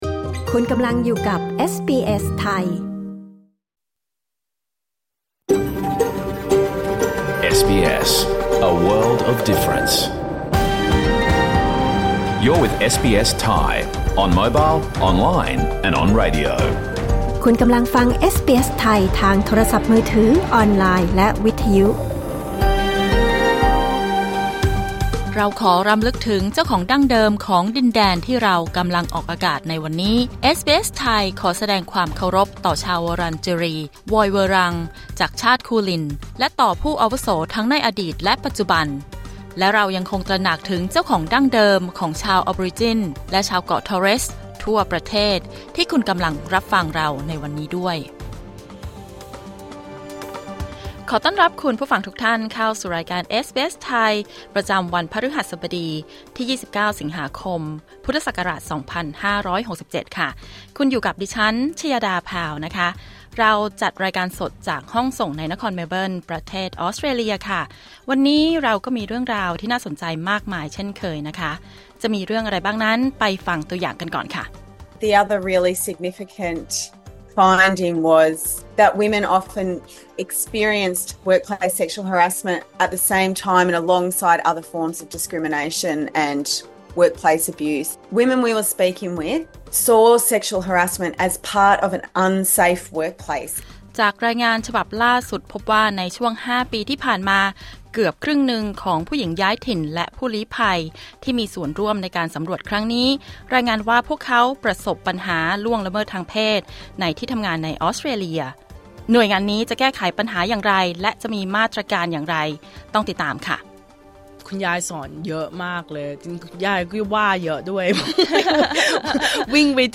รายการสด 29 สิงหาคม 2567